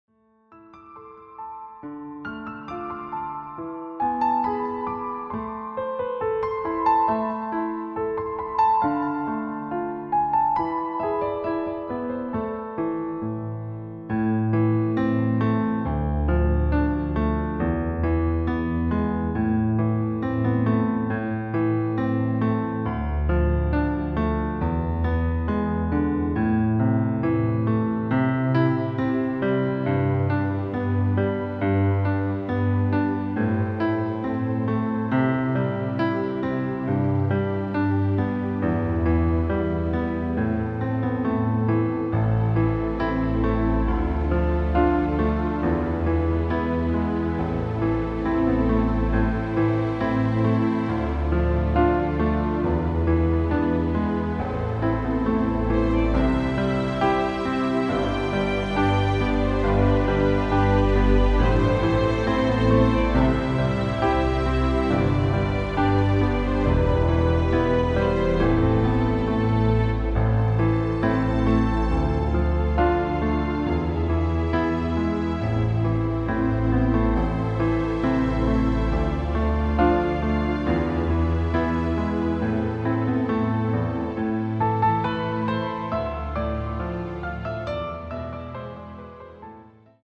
Klavierversion
• Tonart: : A Moll, C Moll, H Moll (weitere auf Anfrage)
• Art: Klavier Streicher
• Das Instrumental beinhaltet NICHT die Leadstimme
Klavier / Streicher